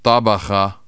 tabaja ØóÈóÎó